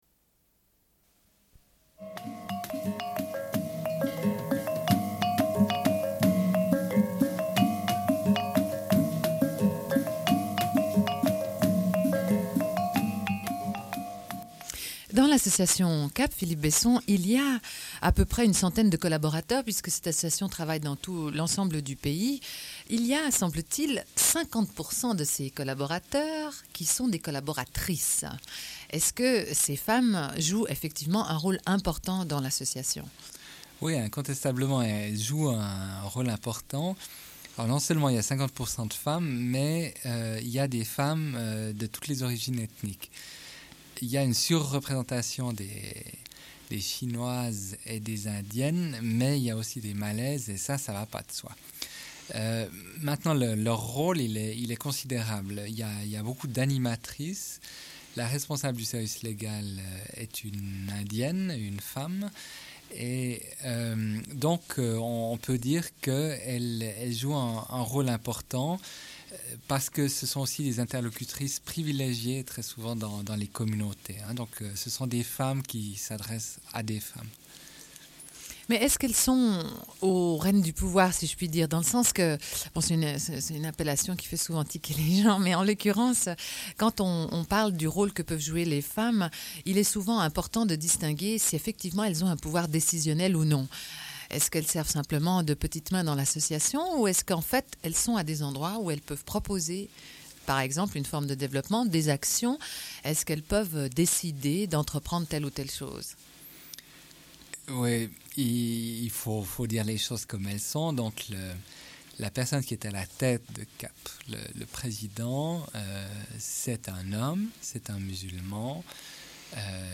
Une cassette audio, face B00:46:47